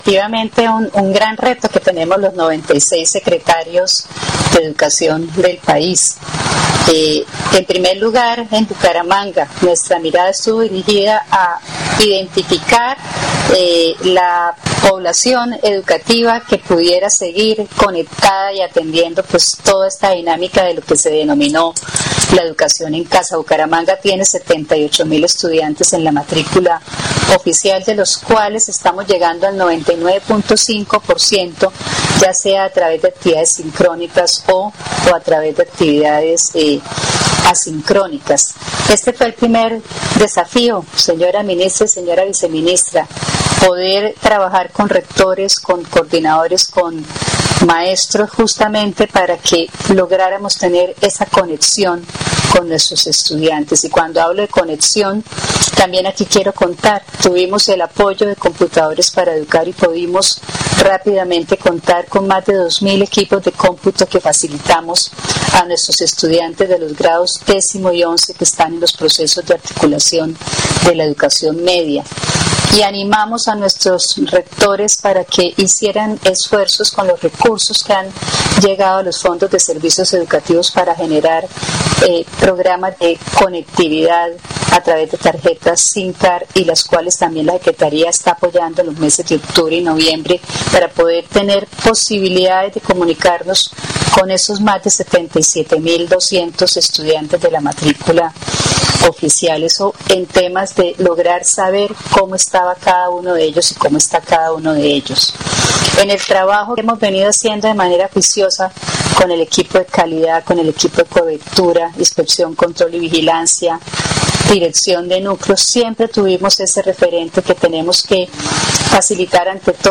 Descargar audio:  Ana Leonor Rueda Vivas, secretaria de Educación de Bucaramanga
El evento concluyó con la realización de un panel que contó con la presencia de la ministra de Educación, María Victoria Angulo, y en el que participó como panelista, la secretaria de Educación de Bucaramanga, Ana Leonor Rueda Vivas.
Ana-Leonor-Rueda-secretaria-educacion-Bucaramanga.mp3